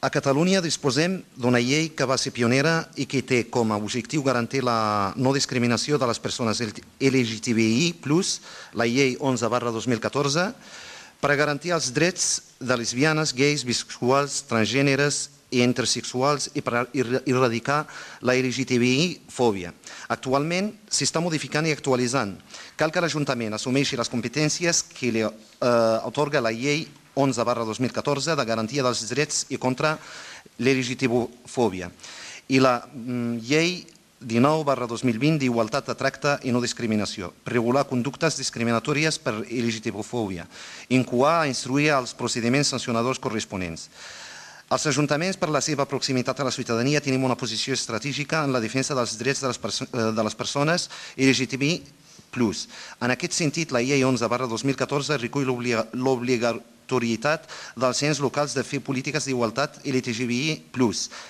Soulimane Messaoudi, portaveu d'ERC Martorell